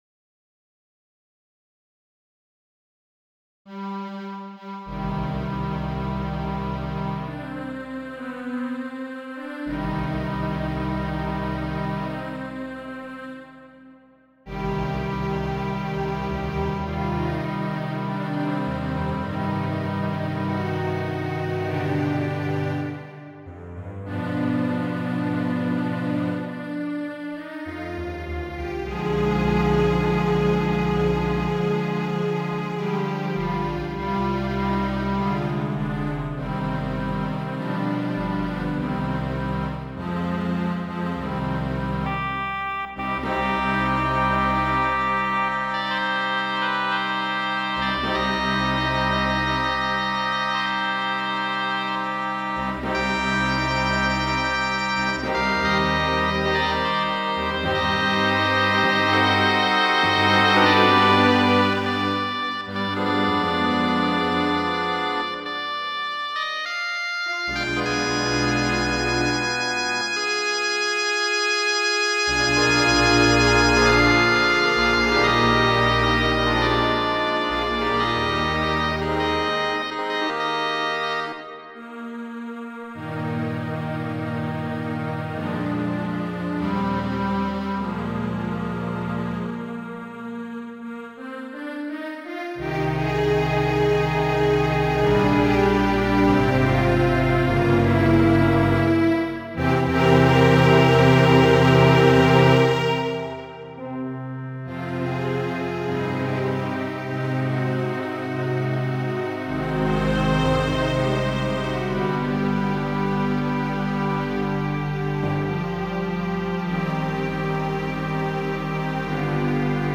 Muziko : Fragmento de la